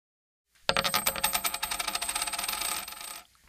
» Монета Размер: 58 кб